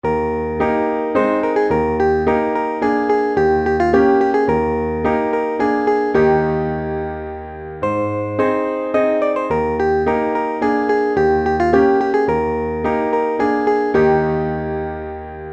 Partitura para piano, voz y guitarra.